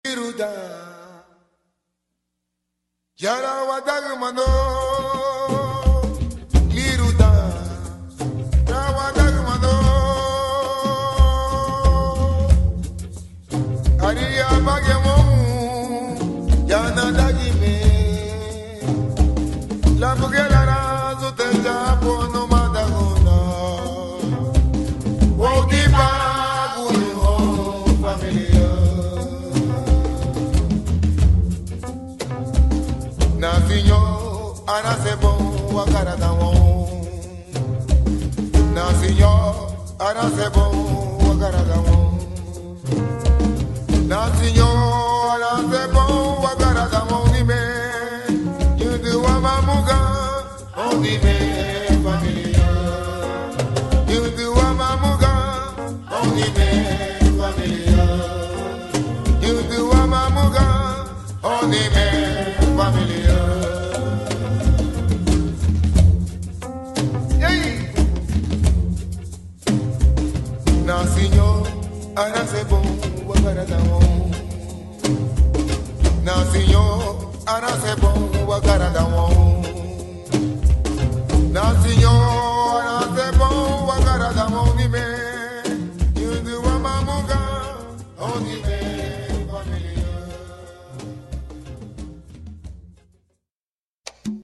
Canción tradicional Garifuna Hondureña
Cancion-tradicional-de-los-garifunas-Hondurenos-small.mp3